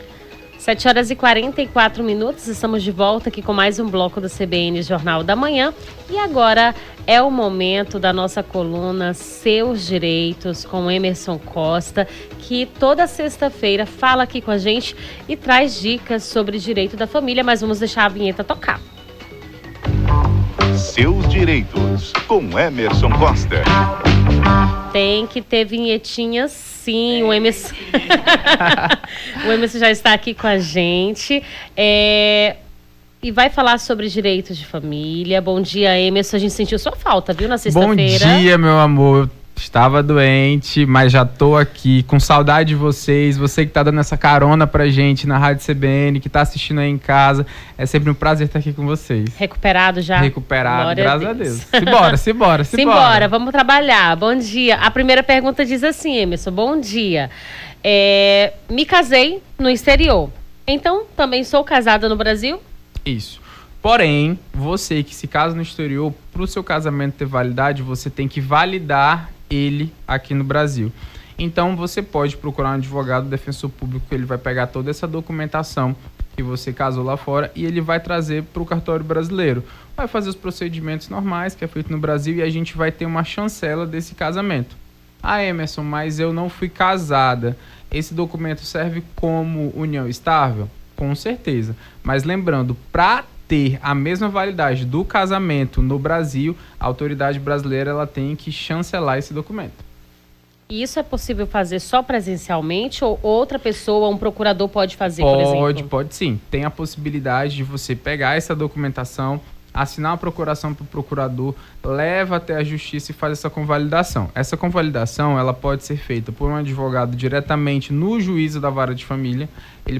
Seus Direitos: advogado tira dúvidas sobre direitos de família